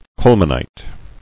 [cole·man·ite]